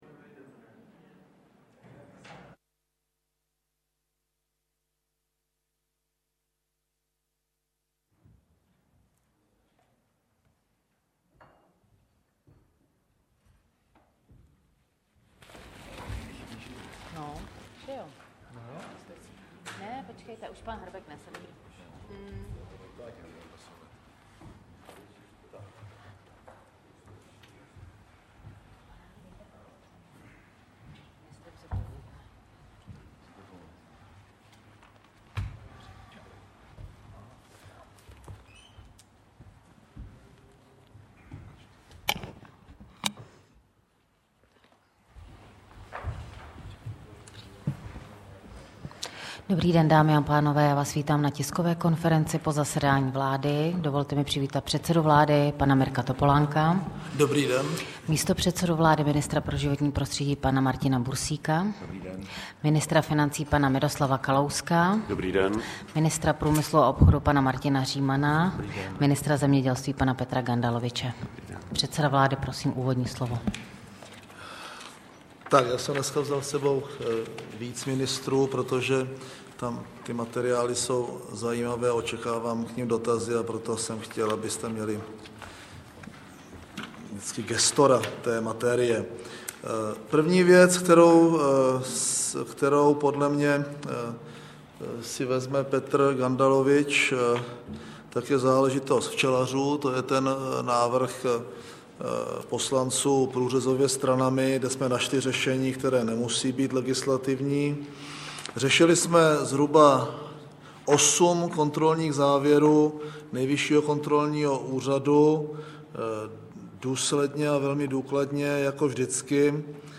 Tisková konference po jednání vlády ČR 21. dubna 2008